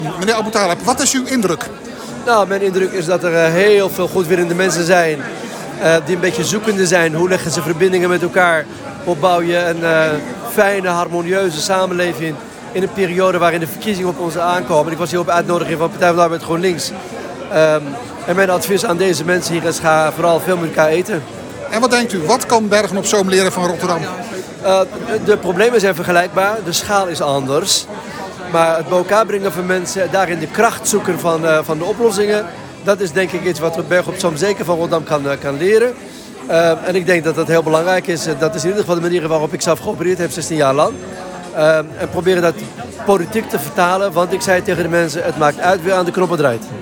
Audio: Ahmed Aboutaleb was vrijdag als spreker te gast in het Huis van de Wijk in Gageldonk.